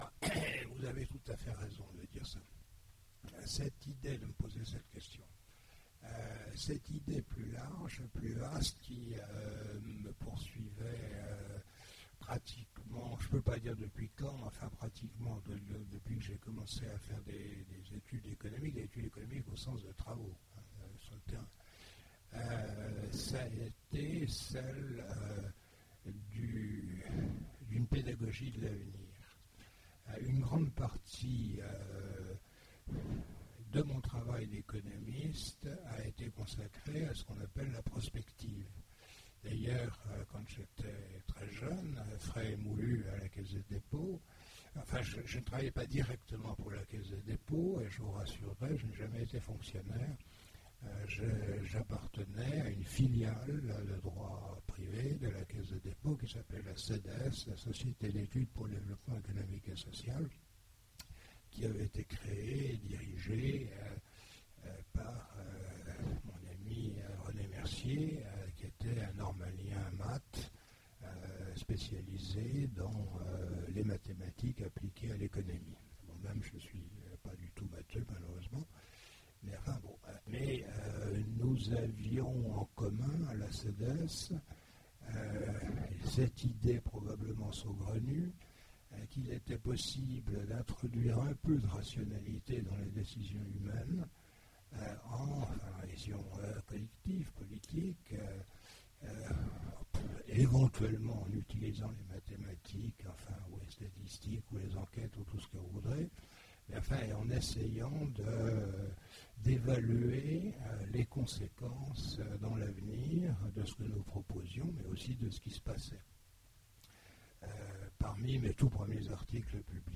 Voici l'enregistrement de la conférence avec Gérard Klein aux rencontres de l'Imaginaire de Sèvres du 12 décembre 2009 à l'occasion des 40 ans d'Ailleurs et demain.
Les questions du public: